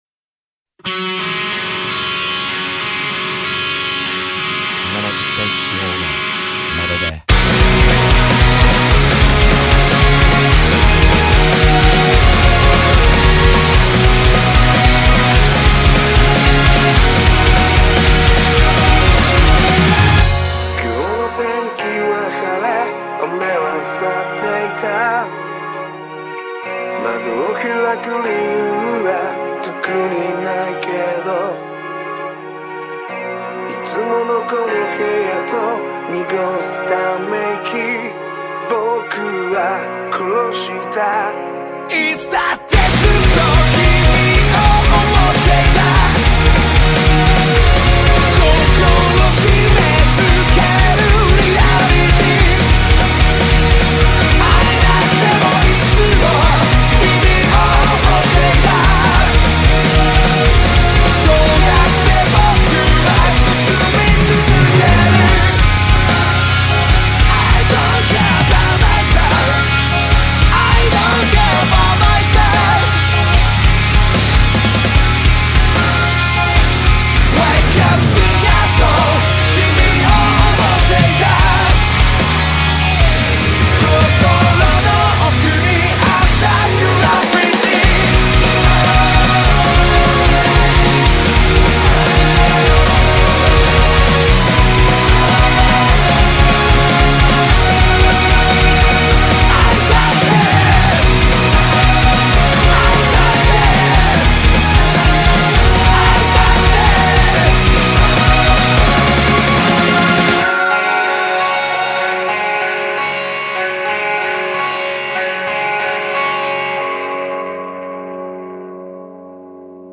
BPM37-149
Audio QualityMusic Cut